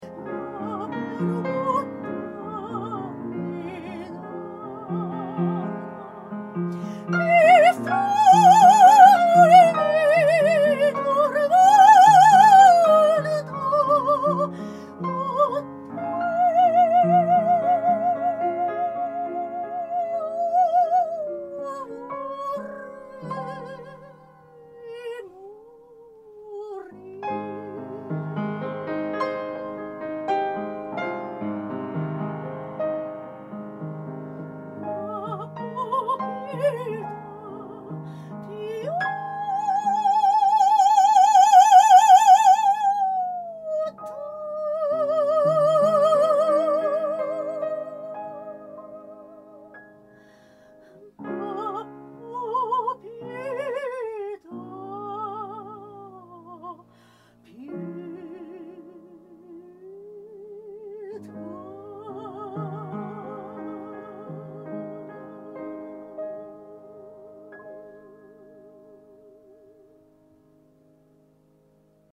今回の収録は、私たち音楽家がスマートフォンを使用した場合というコンセプトで行いました。
まずはSHURE社のスマートフォン専用外付けマイク。
（MV88 iOS デジタル・ステレオ・コンデンサー・マイクロホン）
1_SHURE-MV88用の編集後映像データ.mp3